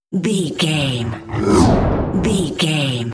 Airy whoosh pass by large
Sound Effects
futuristic
pass by
sci fi